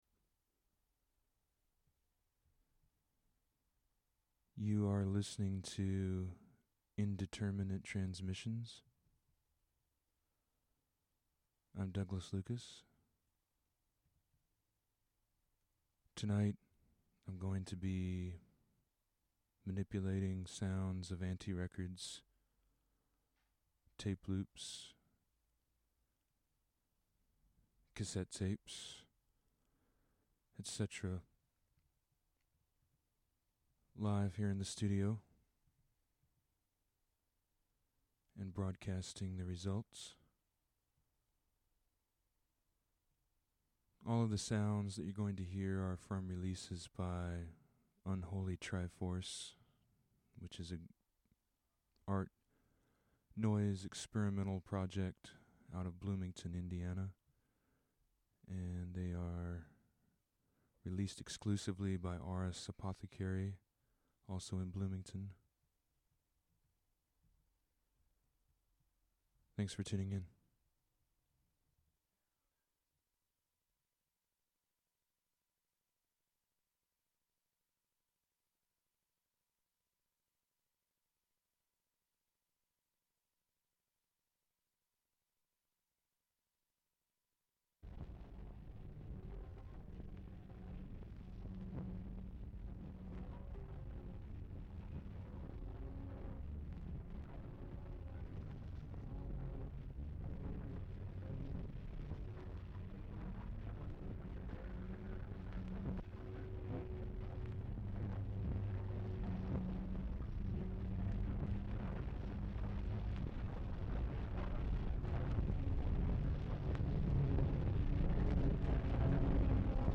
live mix of sounds
forming a caustic composition totaling around 40 minutes.